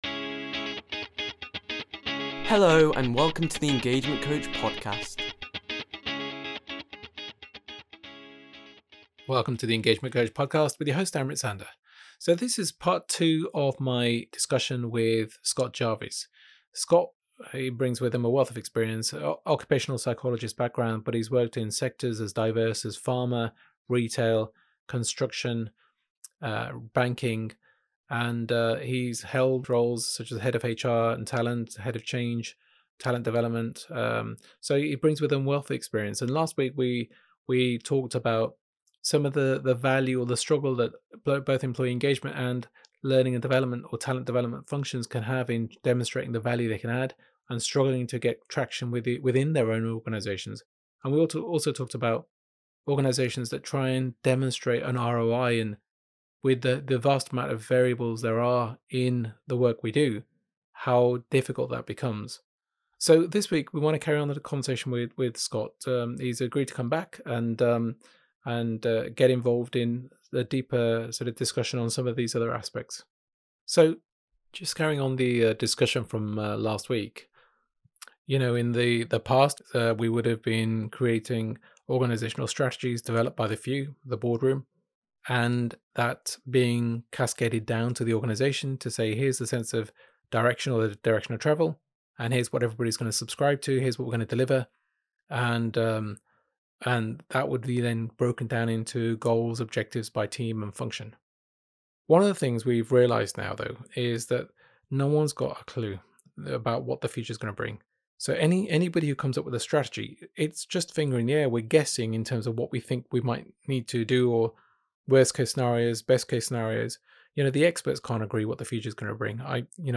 Episode 9: Metaskills and Line Managers - Interview